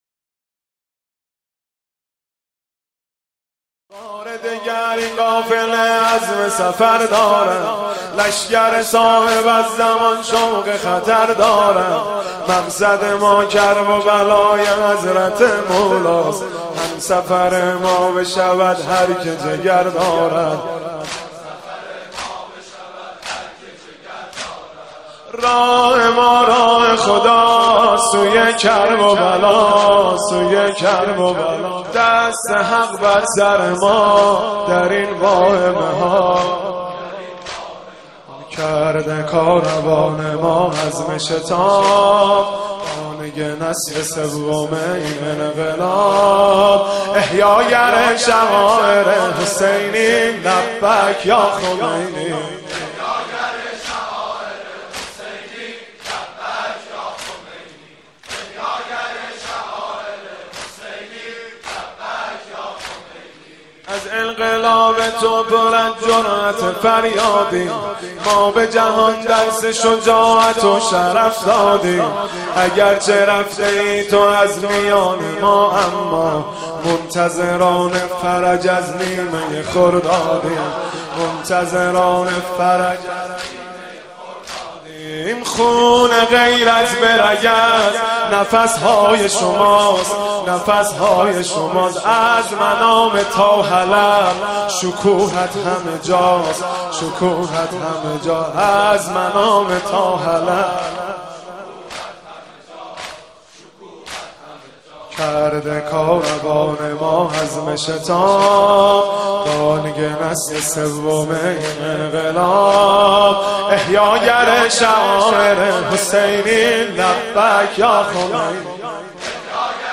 پخش آنلاین نوحه